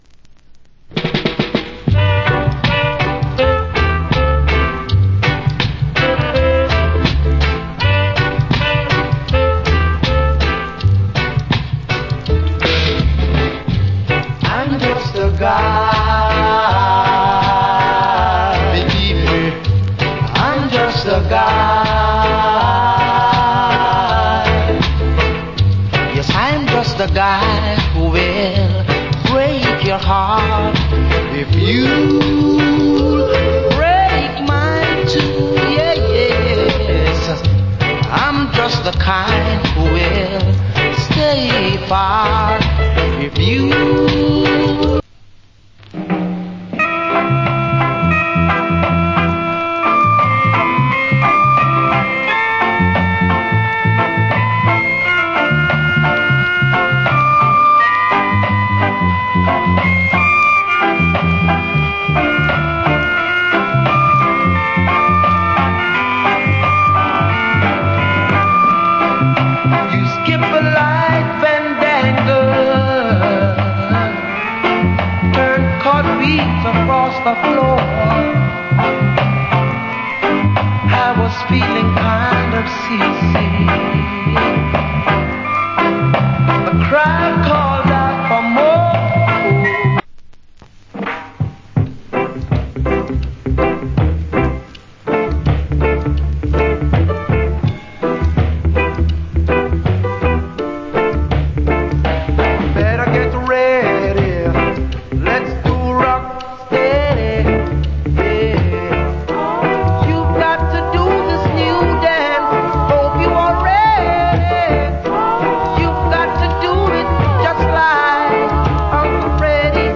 Great Rock Steady.